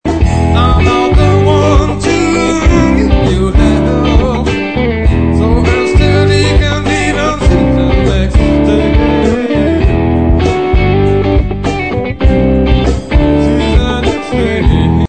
pop-rock